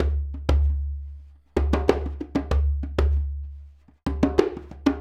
Djembe 07.wav